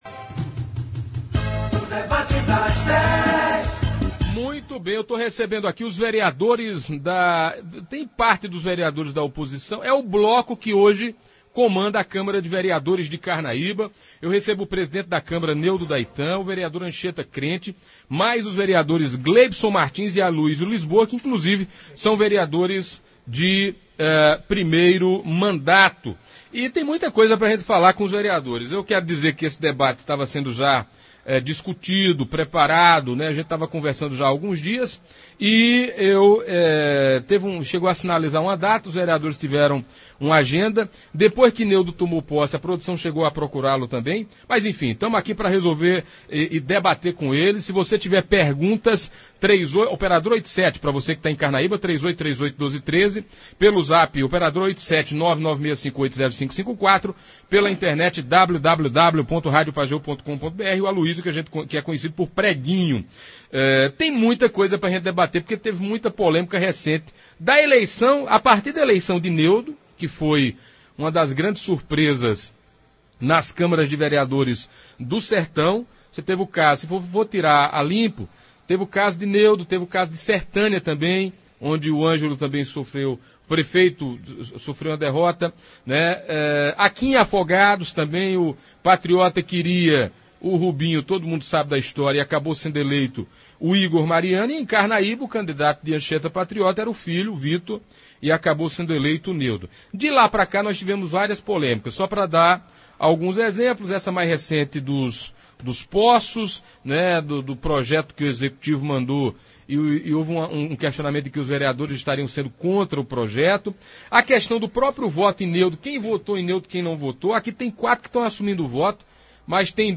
Os vereadores da oposição carnaibana Nêudo da Itã, Anchieta Crente, Aluízio Lisboa, o Preguinho e Gleibson Martins falaram ao Debate das Dez do programa Manhã Total, da Rádio Pajeú, e justificaram os motivos que os fizeram seguir rumo diferente do gestor Anchieta Patriota (PSB), desde a eleição da Mesa Diretora, vencida por Nêudo.